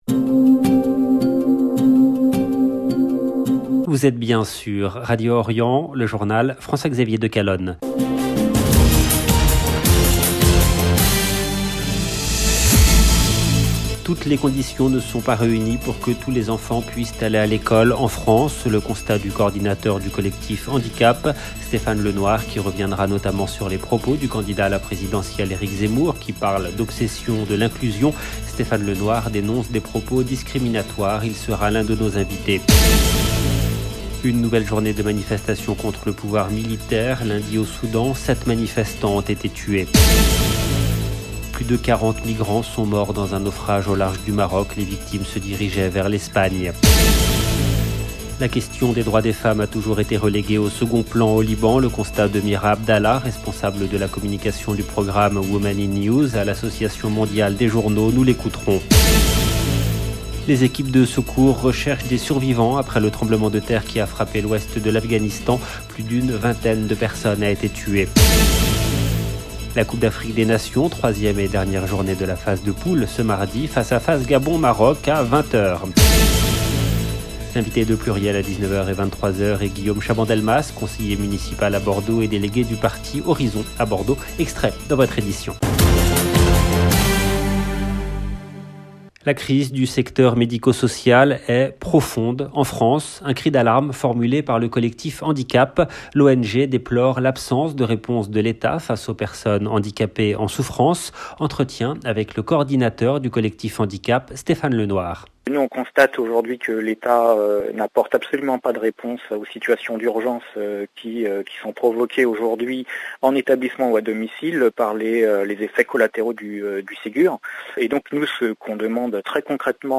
LE JOURNAL DU SOIR EN LANGUE FRANCAISE DU 18/01/22